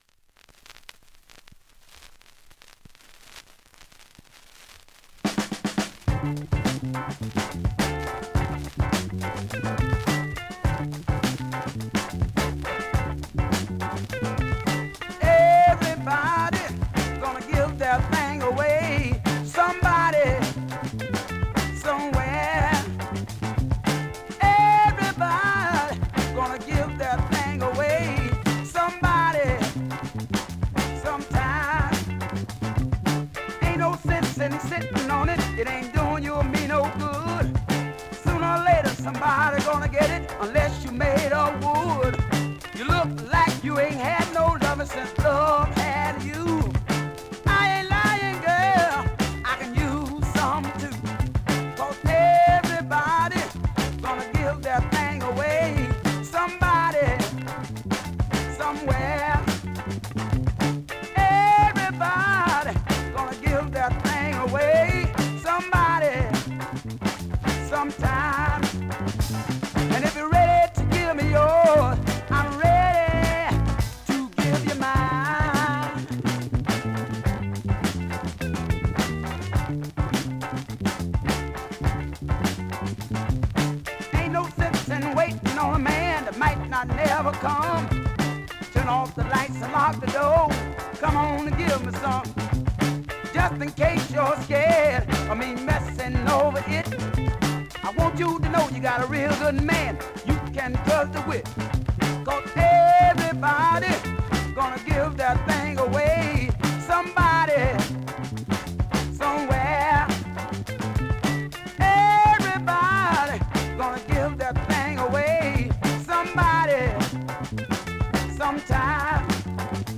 ◆USA盤 オリジナル 7"Single 45 RPM
現物の試聴（両面すべて録音時間５分３６秒）できます。